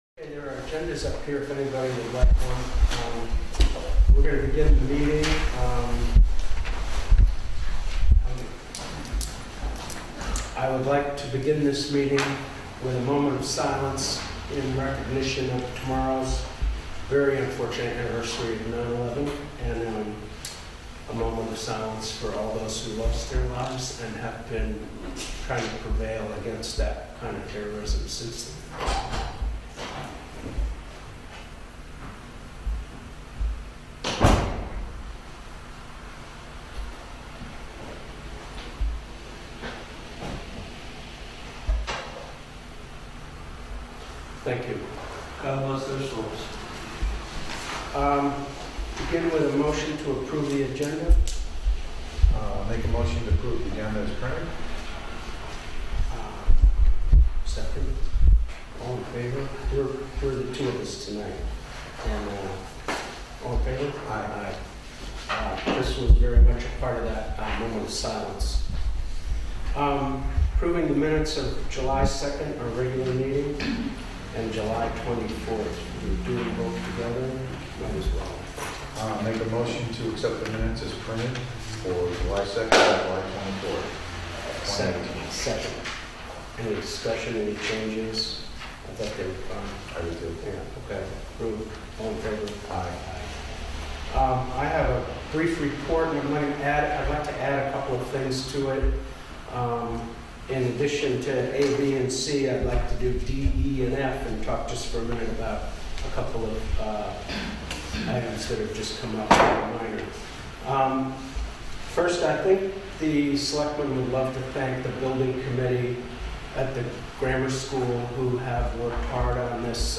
Public Comments on Child Abuse, and Pedophilia at the Salisbury Board of Selectmen Meeting September 10, 2018
Comments from the public on the almost 50-year-old problem of child abuse in Salisbury, CT. Comments from firsthand witnesses of events that happened over the years.
Salisbury-Board-of-Selectmen-9.10.18.mp3